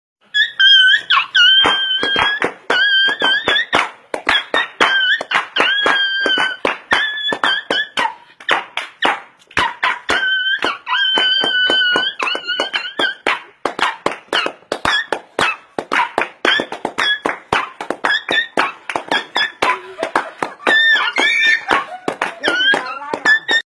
Chillido o Lamento de Perro